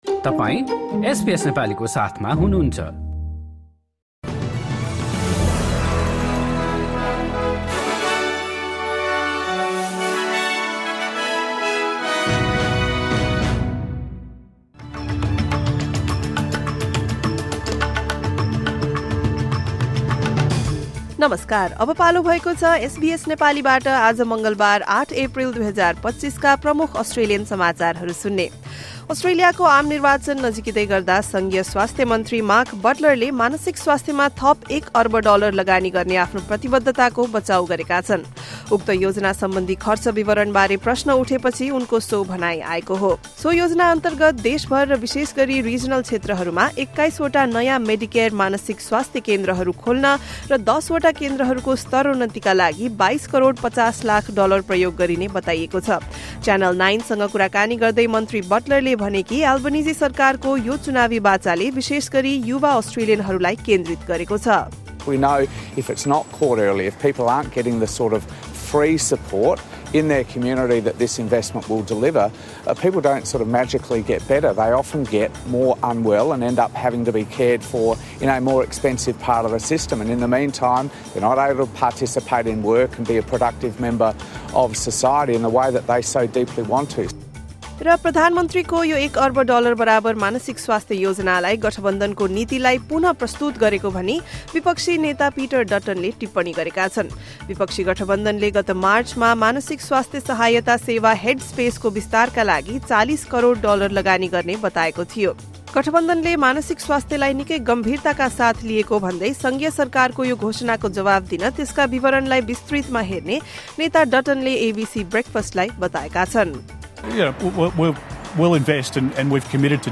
एसबीएस नेपाली प्रमुख अस्ट्रेलियन समाचार: मङ्गलवार, ८ एप्रिल २०२५